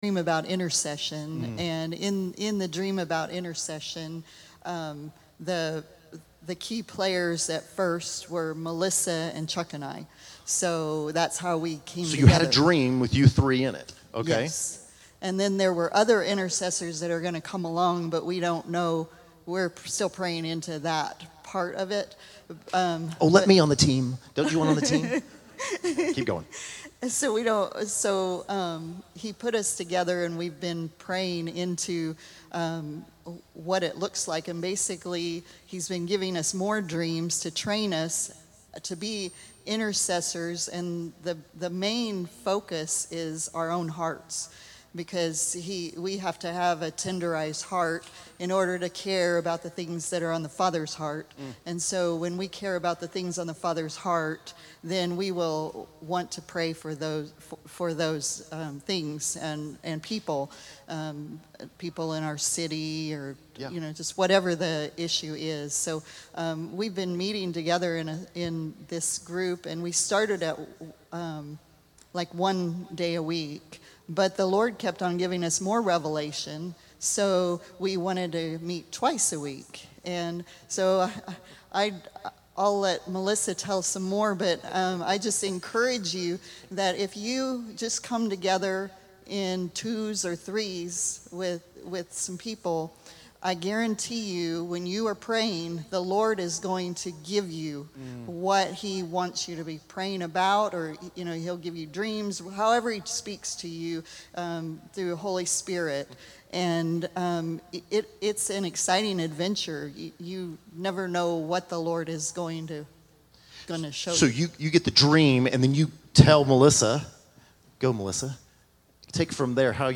September 12, 2020      |     By: Wichita Equip      |      Category: Testimonies      |      Location: Wichita